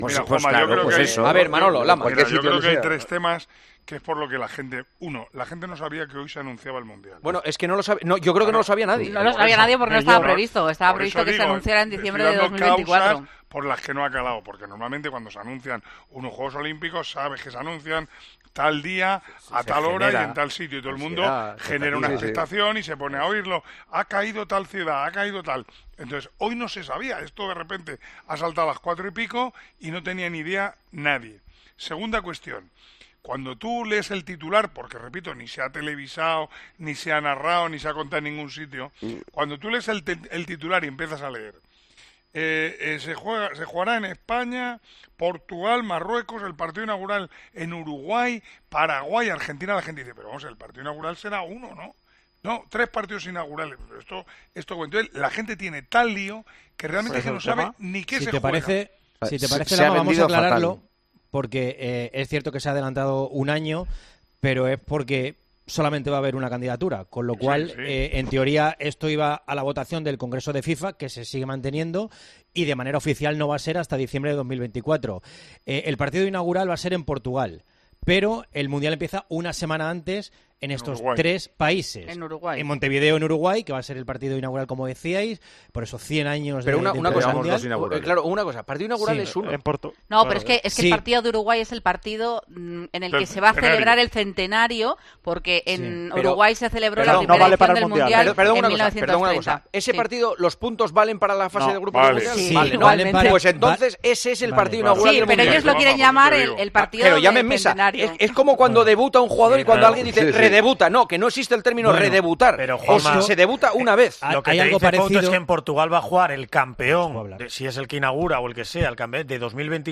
AUDIO: El narrador de Tiempo de Juego y comentarista de El Partidazo de COPE analizó lo que supone que España será la sede del Mundial de Fútbol 2030.